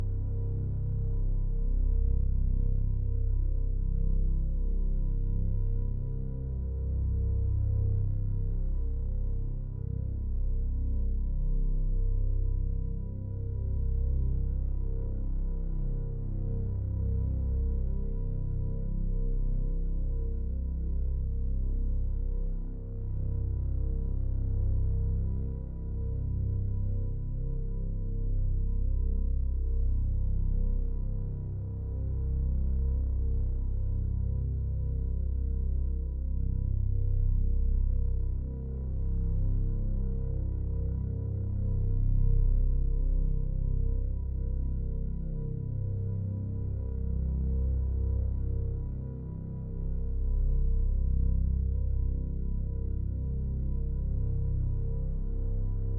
pgs/Assets/Audio/Sci-Fi Sounds/Hum and Ambience/Low Rumble Loop 7.wav at master
Low Rumble Loop 7.wav